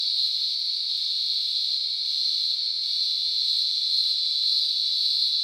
cicadas_night_loop_02.wav